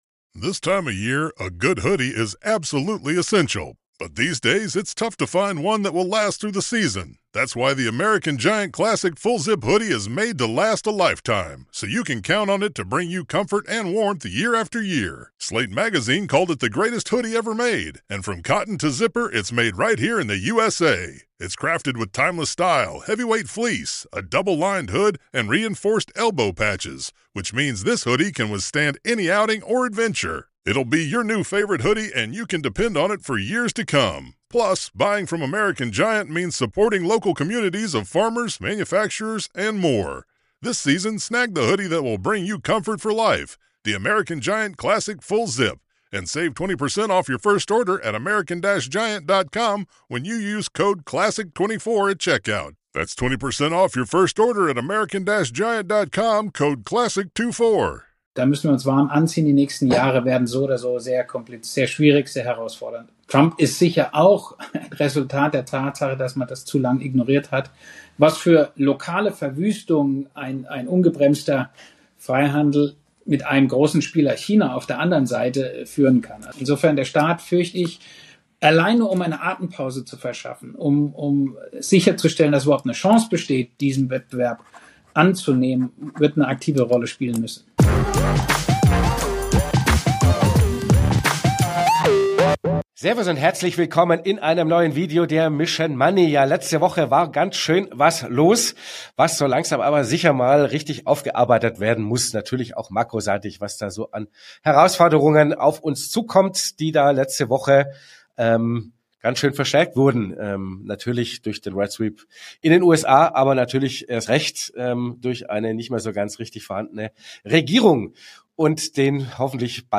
Wir befragen für dich jede Woche die besten Finanz- und Wirtschafts-Experten zu aktuellen Themen rund um dein Geld. powered by FOCUS MONEY
Interview